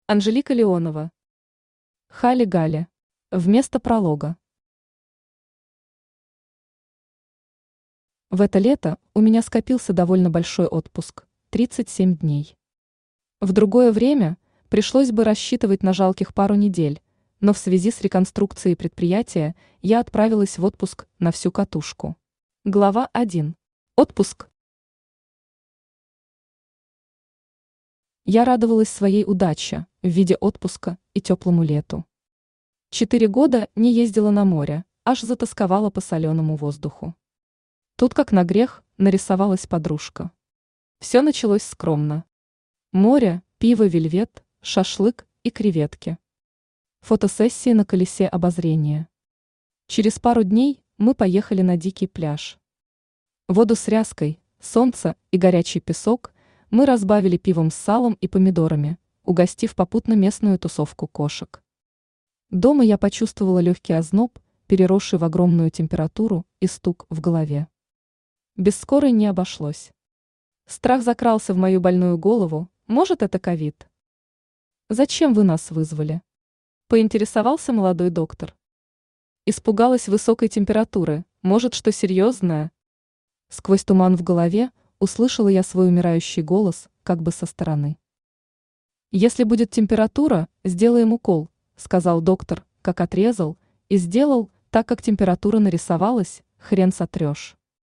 Аудиокнига Хали-гали | Библиотека аудиокниг
Aудиокнига Хали-гали Автор Анжелика Александровна Леонова Читает аудиокнигу Авточтец ЛитРес.